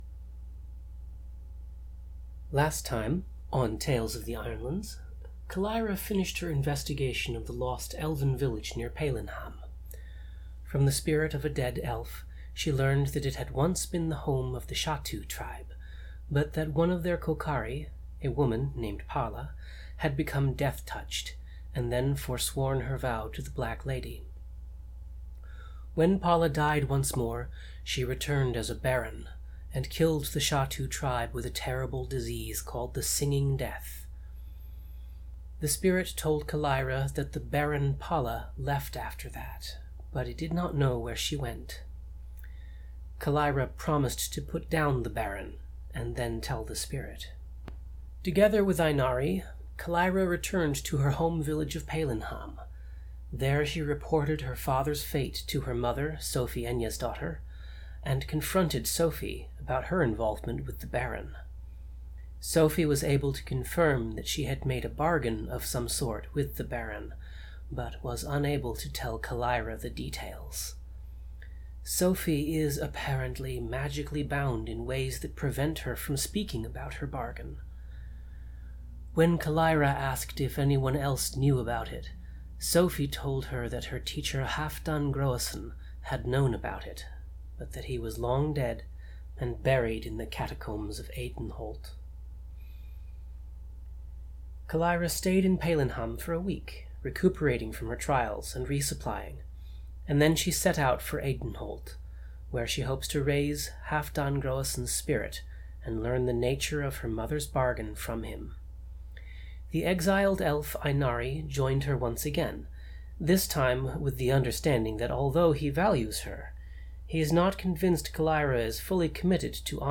Note: This has been edited to remove uninteresting bits such as silence, throat-clearing and paper shuffling.